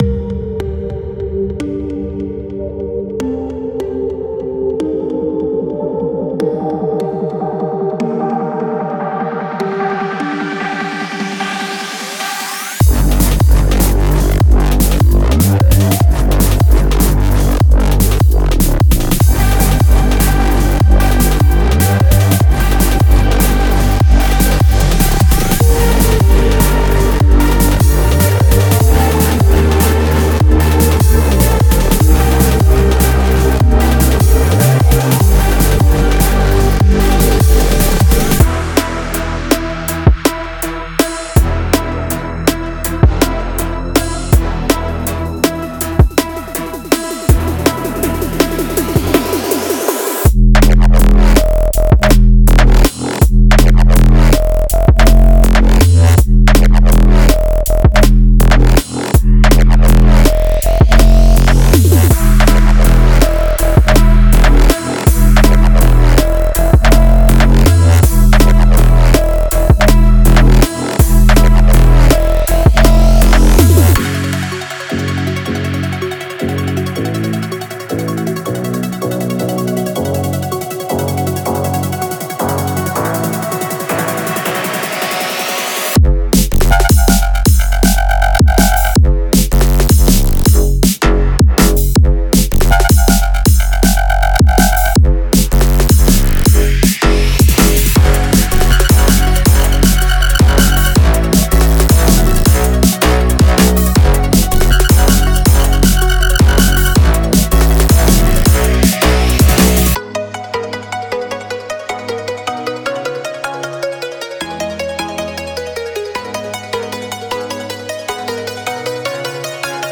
Genre:Drum and Bass
ワンショットはクリーンかつ精密で、オリジナルのグルーヴ構築や既存のヒット音とのレイヤーにも最適です。
また、FXやアトモスフィア系の要素も含まれており、トランジションやブレイクダウンに煌めきや緊張感を加えるのも簡単です。
これは、メロディと動き、そして現代的で商業的な魅力を兼ね備えた「気分を高揚させるアドレナリン」のようなサウンドです。
デモサウンドはコチラ↓